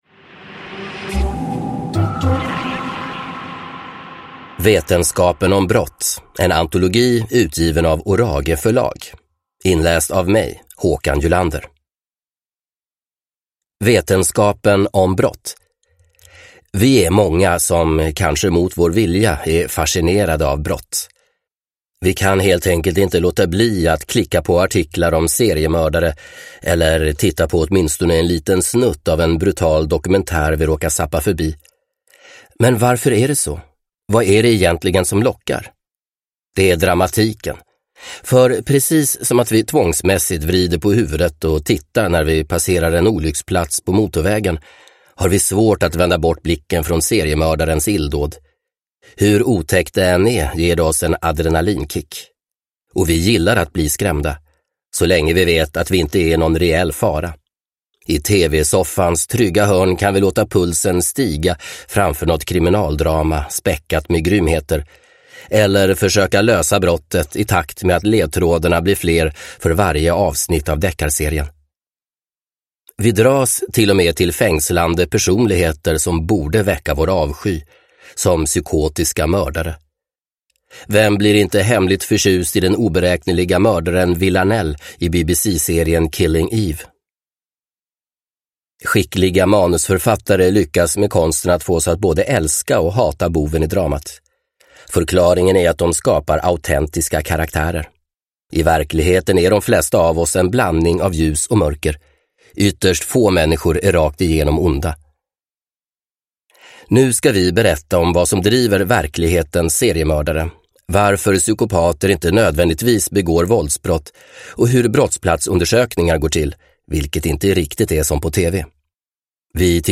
Vetenskapen om brott – Ljudbok